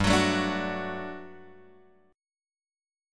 GM7sus2 chord {3 x 4 2 3 2} chord